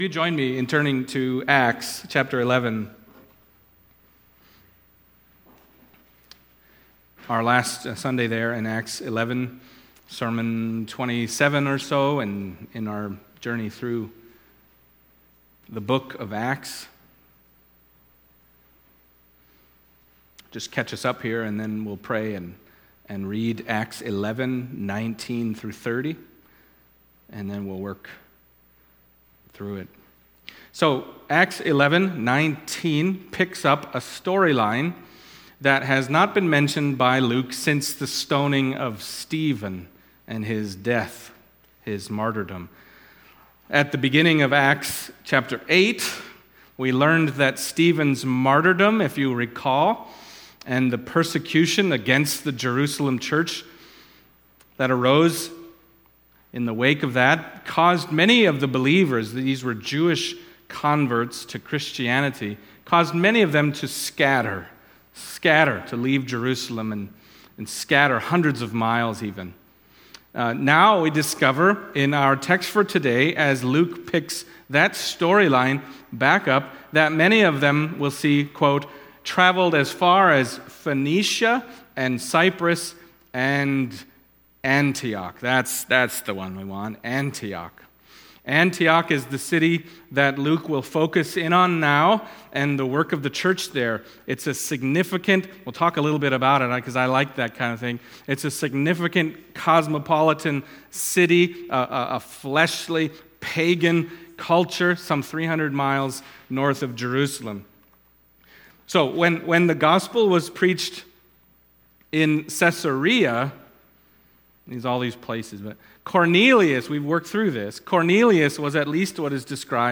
Acts Passage: Acts 11:19-30 Service Type: Sunday Morning Acts 11:19-30 « And Also to the Gentiles